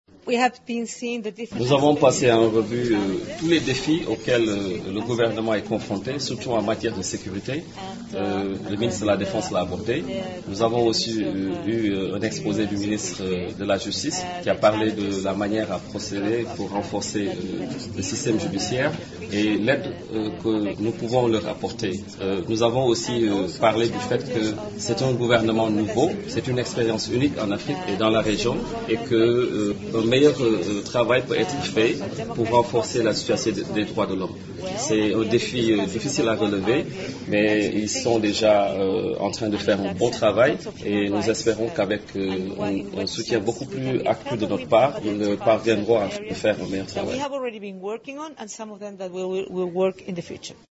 Mais ils sont déjà en train de faire un bon travail », a fait remarquer Mme Michelle Bachelet au sortir de l’audience à la Primature.